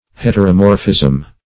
Search Result for " heteromorphism" : The Collaborative International Dictionary of English v.0.48: Heteromorphism \Het`er*o*mor"phism\, Heteromorphy \Het`er*o*mor"phy\, n. (Biol.) The state or quality of being heteromorphic.